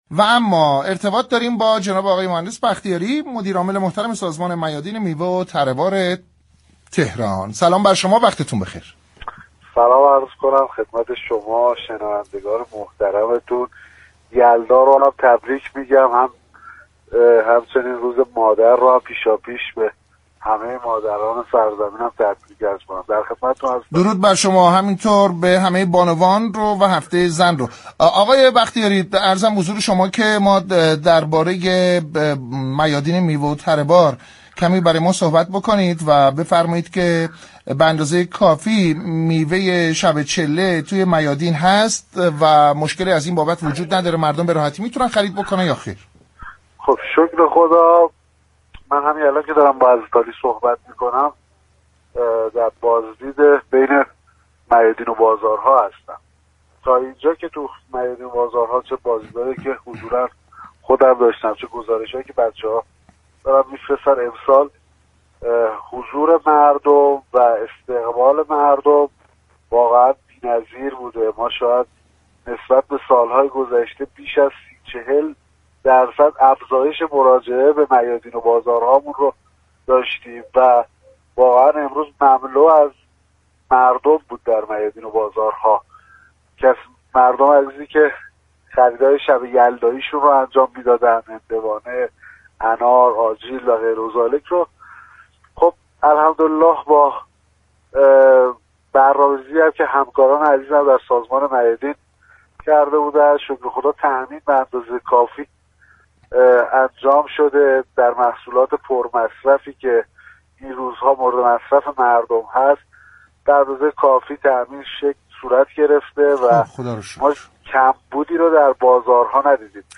به گزارش پایگاه اطلاع رسانی رادیو تهران، مهدی بختیاری زاده، مدیرعامل سازمان میادین میوه و تره بار شهرداری تهران در گفتگو با برنامه «اینجا تهران است» روز پنجشنبه 29 آذر ضمن تبریك شب یلدا و سالروز ولادت حضرت زهرا(س) با بیان اینكه امسال حدود 30 تا 40 درصد مراجعه مردم به میادین تره‌بار برای خرید اقلام شب یلدا افزایش داشته است گفت: محصولات پر مصرف این روزها به مقدار كافی تامین شده و كمبود نداریم و مردم هم رضایت نسبی دارند.